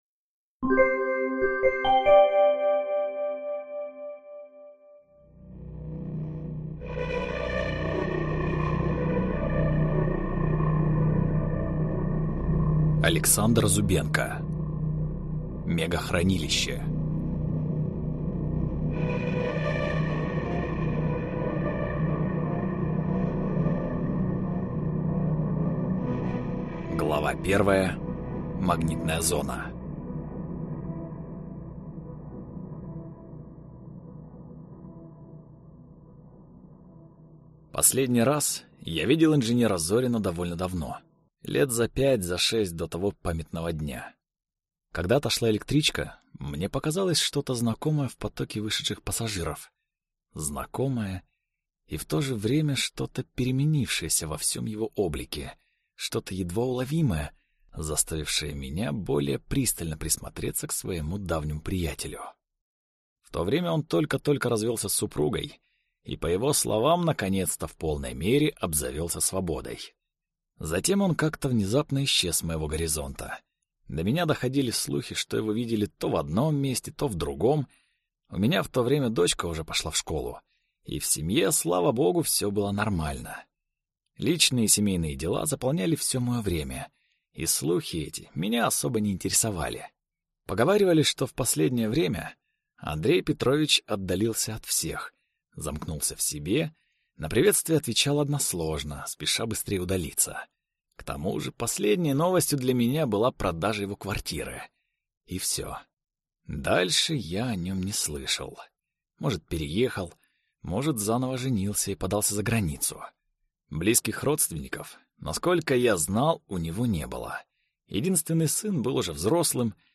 Скачать, слушать онлайн аудиокнигу Мегахранилище автора Зубенко Александр Борисович